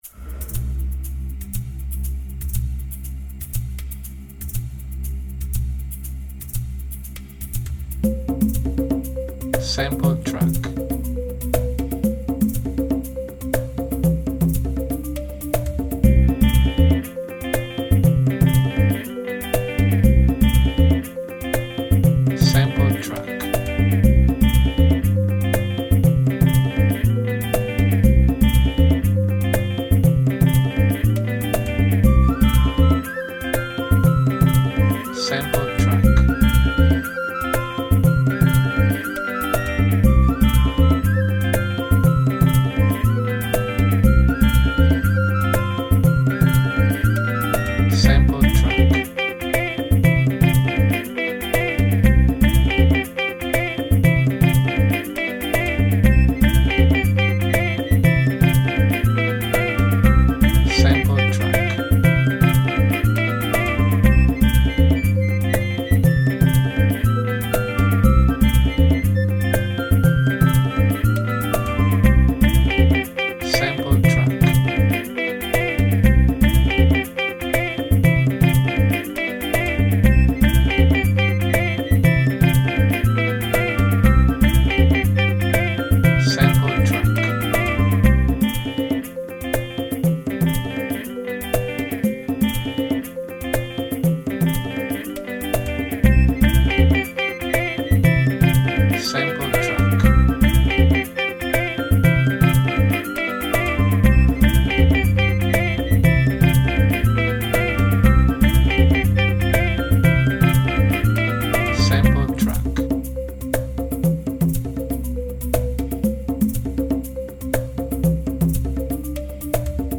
AFRICA Piffero, tipici riff di chitarra e ritmo incalzante.